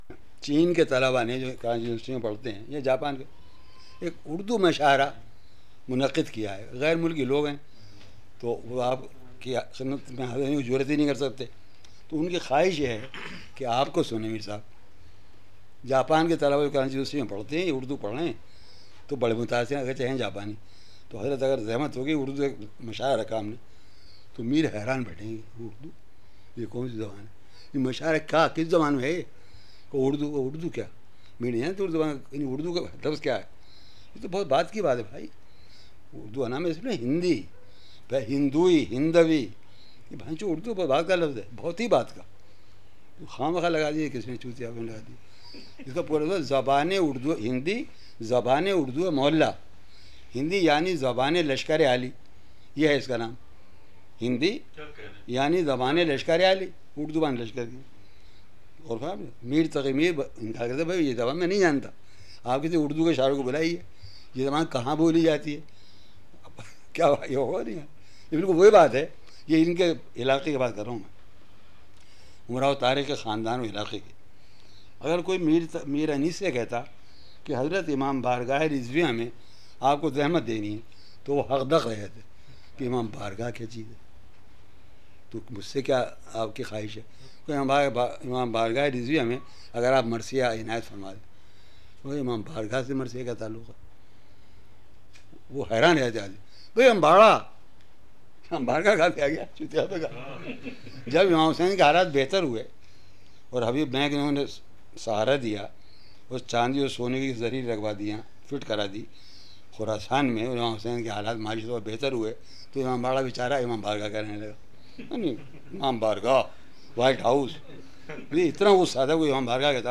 Following is a very rare - albeit controversial talk - on Urdu and some rituals of Shia sect (He himself belong to a very reputed literary Shia family). Speech contains urdu curse words (C****, BC etc.) It tells us how Shia and Sunni sects lived peacefully in India just in recent past!